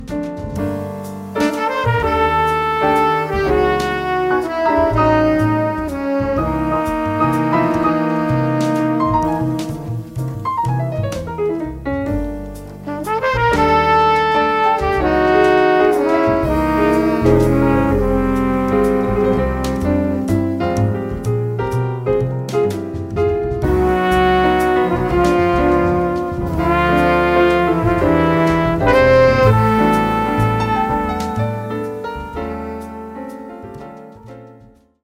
really flowing jazz waltz
with a lovely horn arrangement